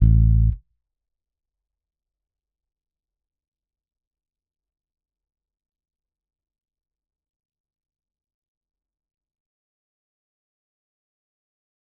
808s
Bass Zion 2.wav